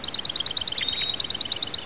Sparrow.wav